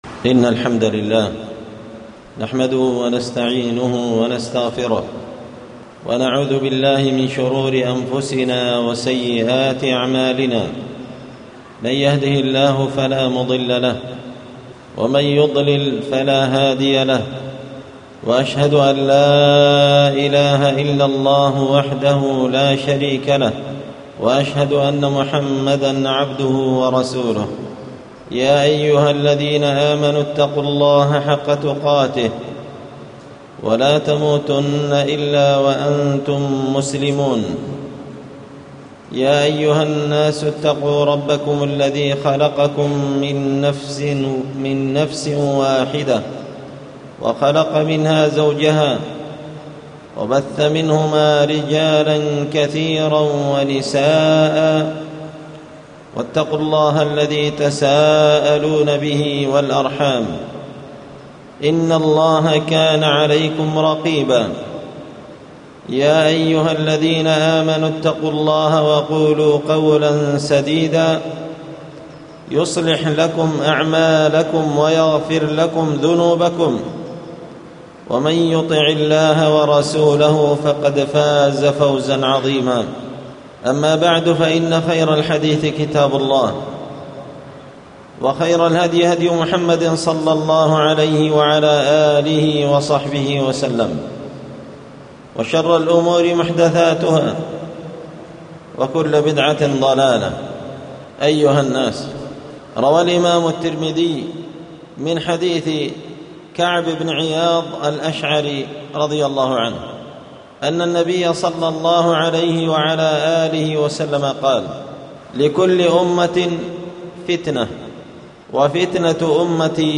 خطبة جمعة بعنوان
ألقيت هذه الخطبة بمسجد الجامع بصقر